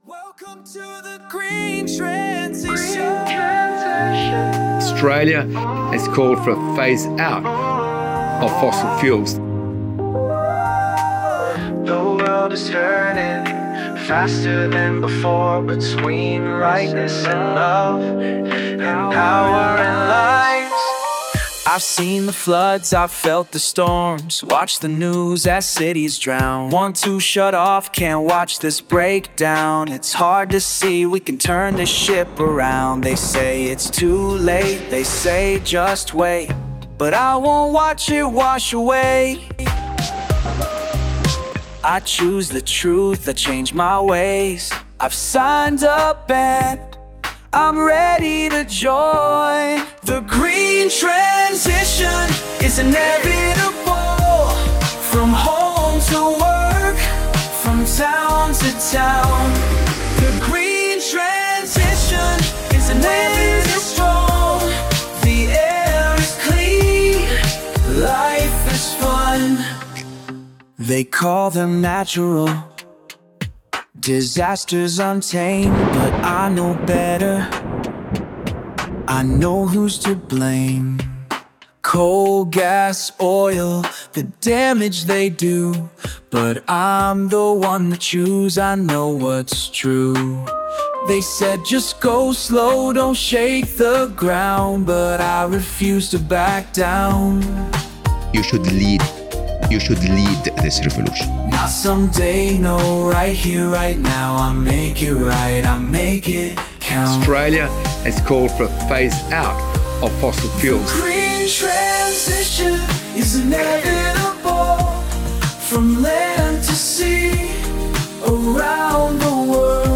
– A soaring call to embrace a better world, already within reach, inspired by our discussions in The Sustainable Hour no. 551